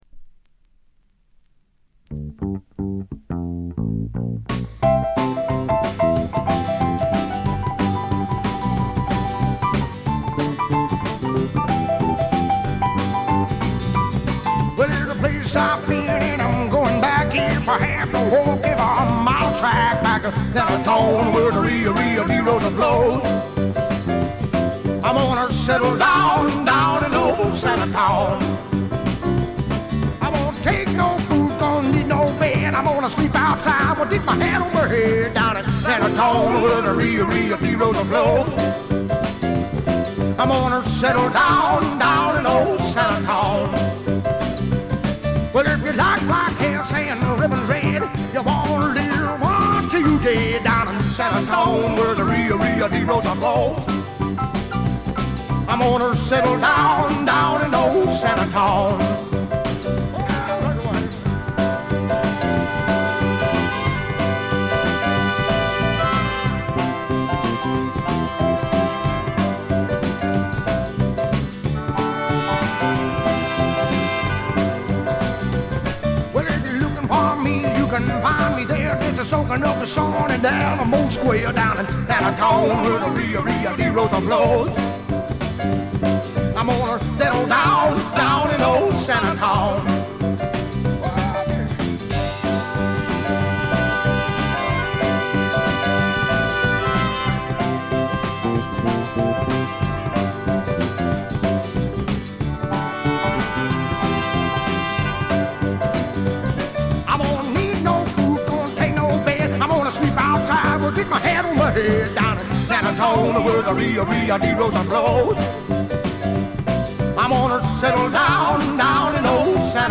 部分試聴ですが、ほとんどノイズ感無し。
肝心の音はといえば南部の湿った熱風が吹きすさぶ強烈なもの。
試聴曲は現品からの取り込み音源です。